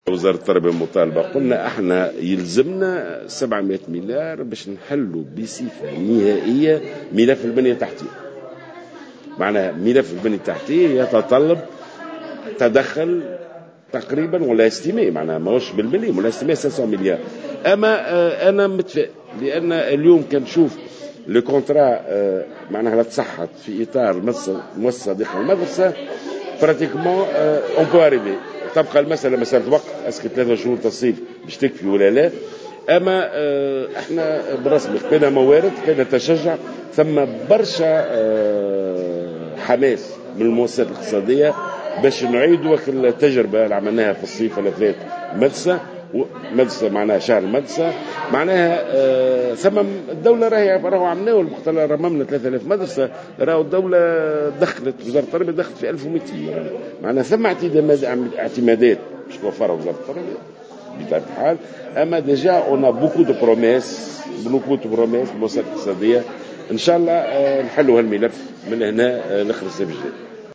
وأضاف الوزير على هامش الاستماع له من قبل لجنة شؤون ذوي الإعاقة بمجلس نواب الشعب أن ملف البنية التحتية للمدارس التونسية يستوجب اعتمادات بحوالي 700 مليون دينار، مشيرا إلى أن عددا من أصحاب المؤسسات الاقتصادية عبروا عن رغبتهم في المساهمة من أجل ترميم وإعادة صيانة بعض المدارس.